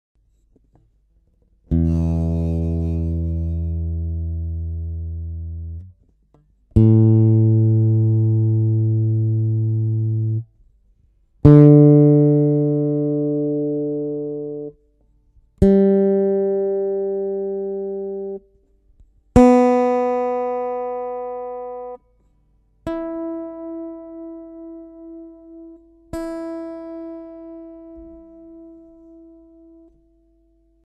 mi la re sol si mi.mp3
mi-la-re-sol-si-mi.mp3